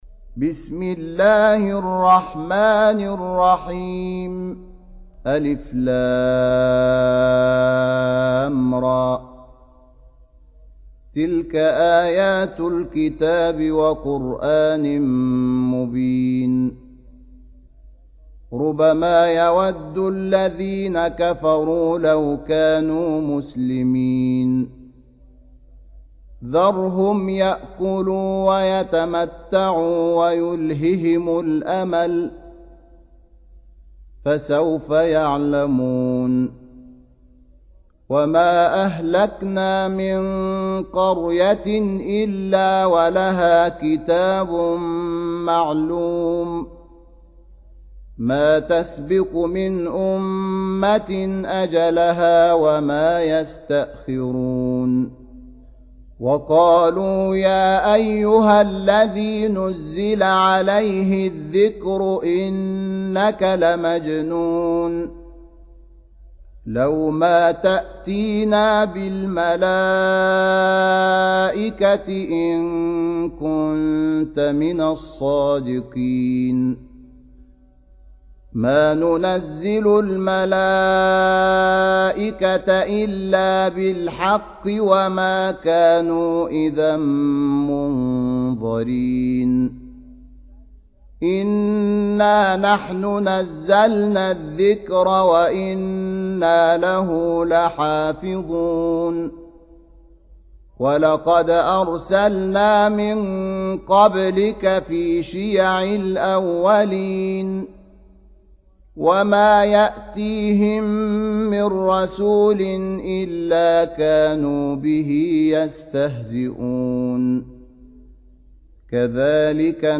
15. Surah Al-Hijr سورة الحجر Audio Quran Tarteel Recitation Home Of Sheikh Shahat Muhammad Anwar :: الشيخ شحات محمد انور
Surah Repeating تكرار السورة Download Surah حمّل السورة Reciting Murattalah Audio for 15.